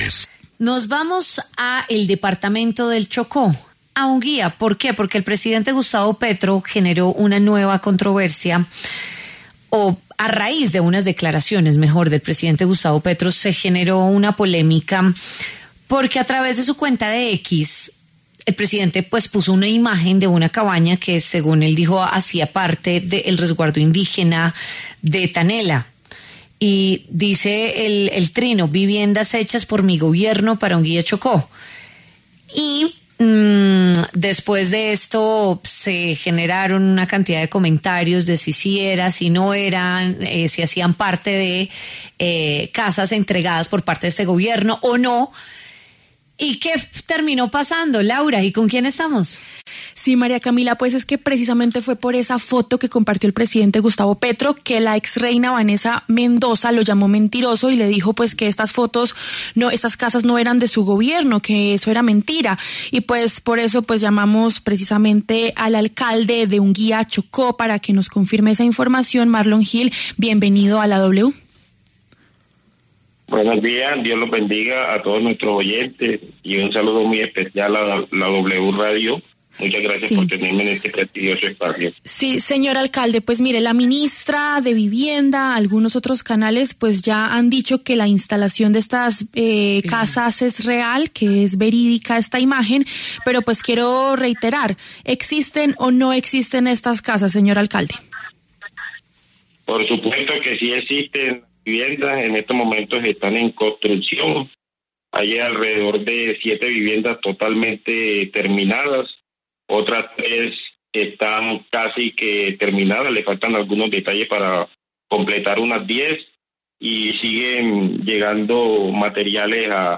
Escuche la entrevista al alcalde Marlon Gil en W Fin de Semana: